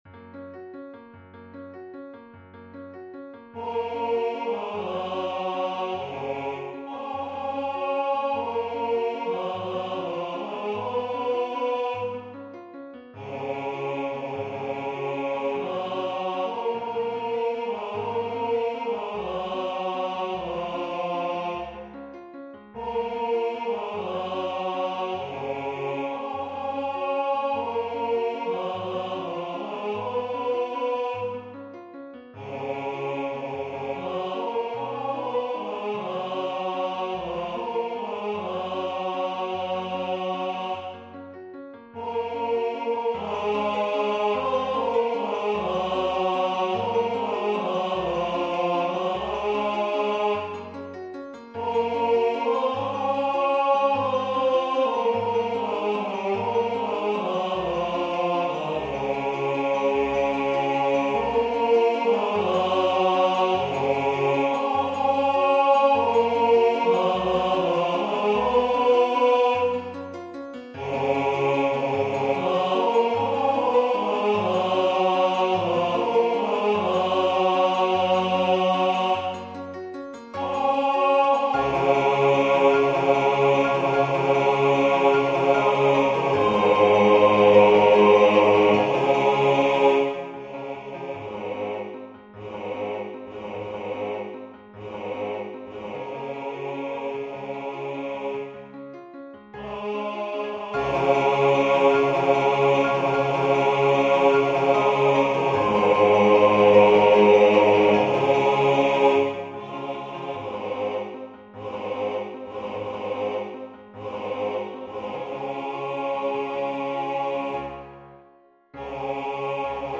Bass
SpeedYourJourney_bass.mp3